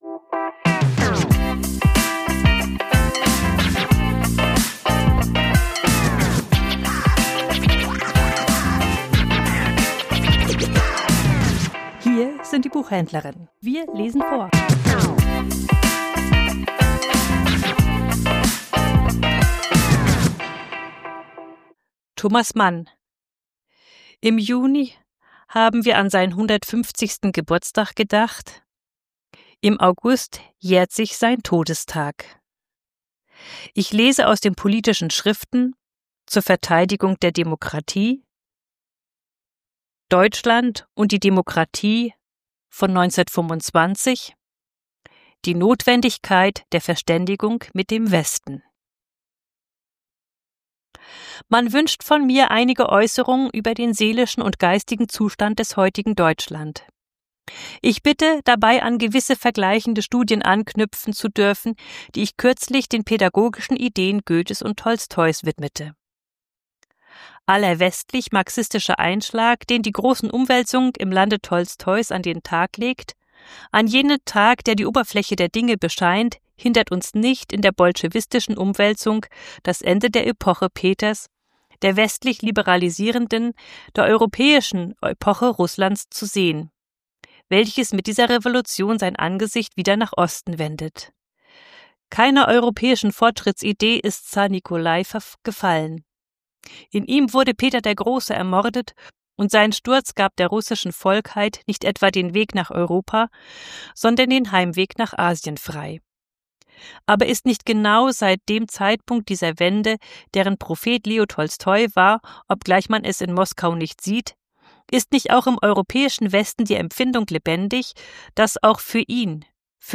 Vorgelesen: Thomas Mann - Zur Verteidigung der Demokratie ~ Die Buchhändlerinnen Podcast